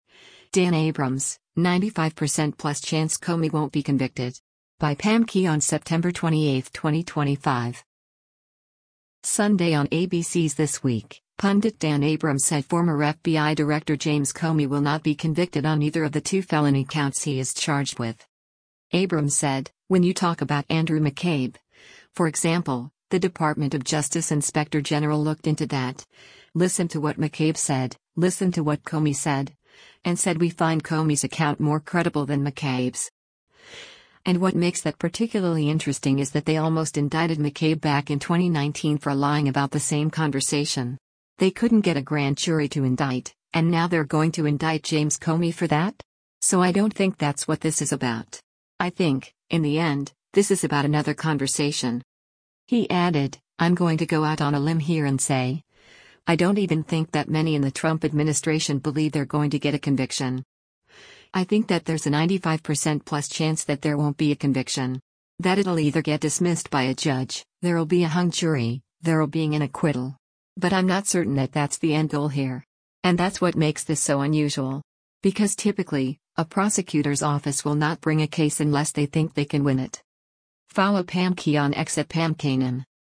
Sunday on ABC’s “This Week,” pundit Dan Abrams said former FBI Director James Comey will not be convicted on either of the two felony counts he is charged with.